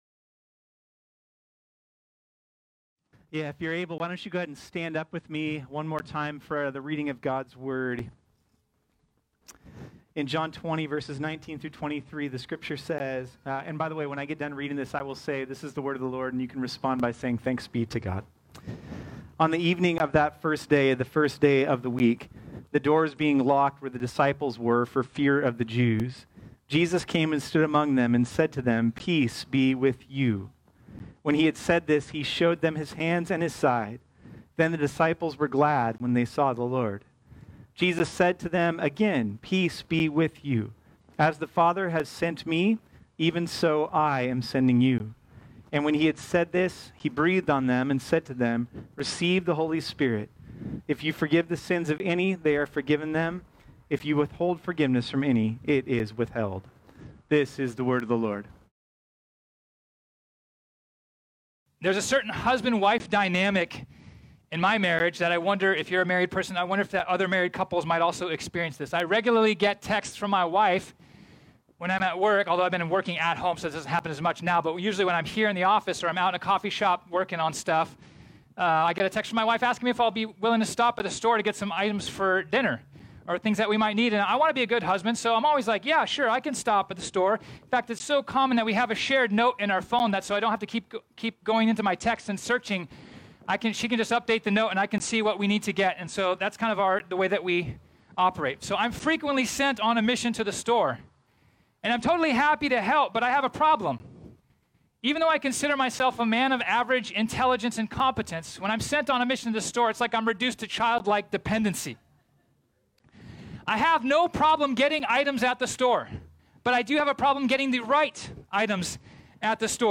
This sermon was originally preached on Sunday, August 16, 2020.